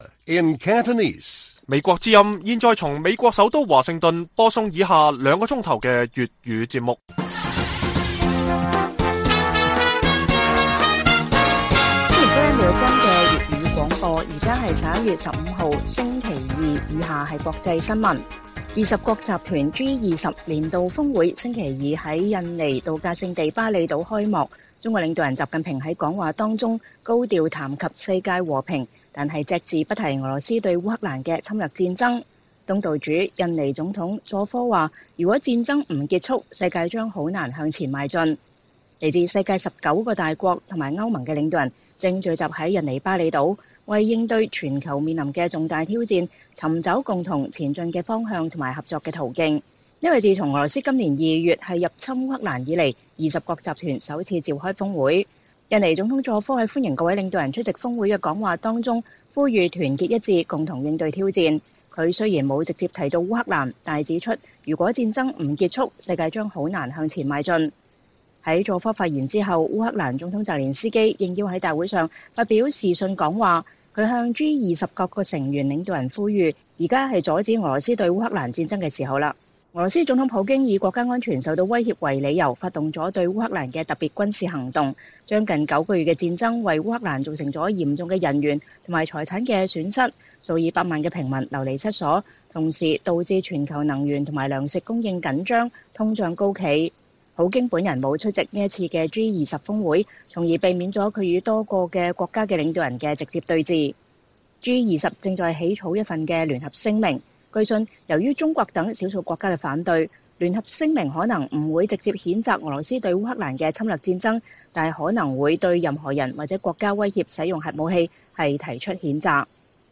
粵語新聞 晚上9-10點: G20年度峰會開幕習近平高調談和平但不提俄羅斯對烏戰爭